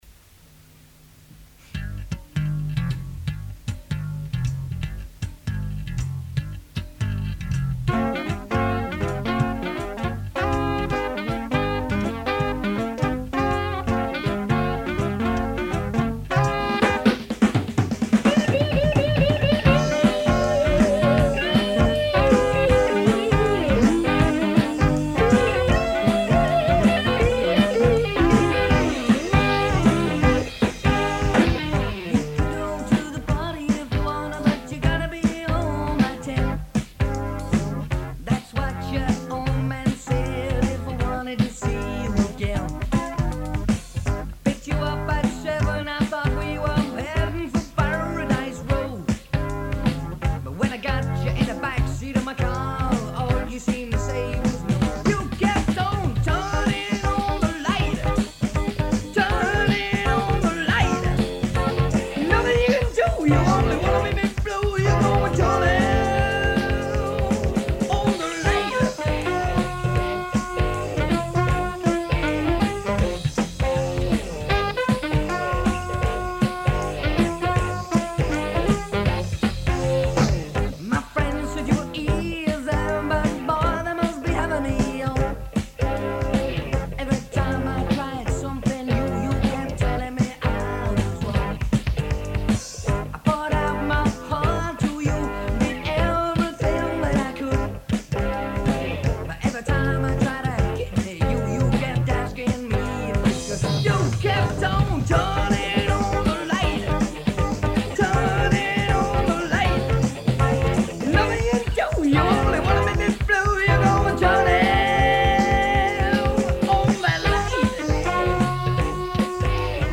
being played live in the studio, no multi-tracking.